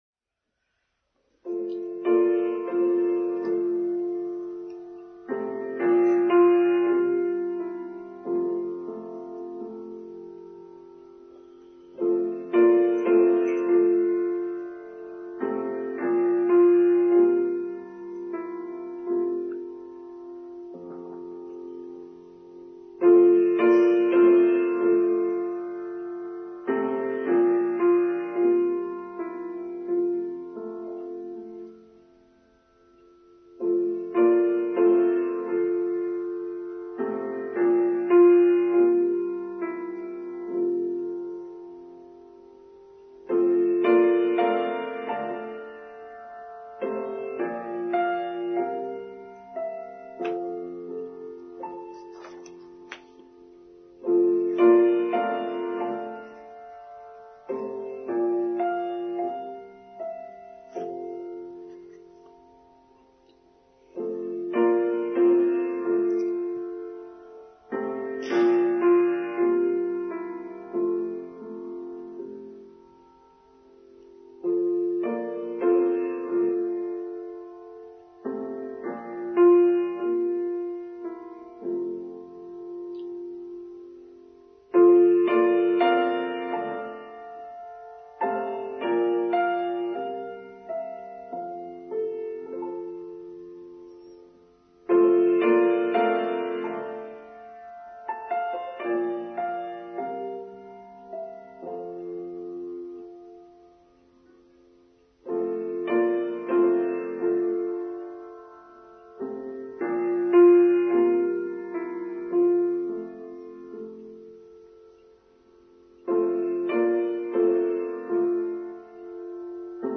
Going Deep: Online Service for Sunday 10th July 2022